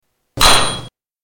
Tuning fork 8
Tags: Tuning Fork Tuning Fork sounds Tuning Fork clips Tuning Fork sound Sound effect